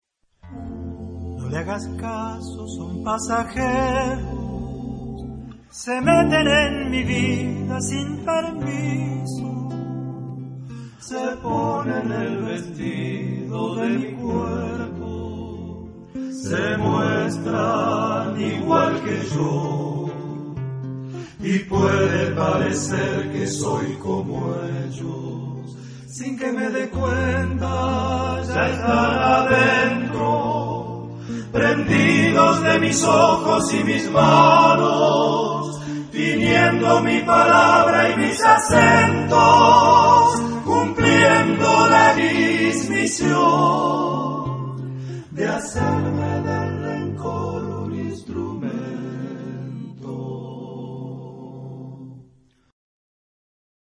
Género/Estilo/Forma: Profano ; Folklore ; Latino Americano
Carácter de la pieza : afectuoso
Tipo de formación coral: TTBBB  (5 voces Coro de hombres )